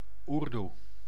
Ääntäminen
IPA: /ˈurdu/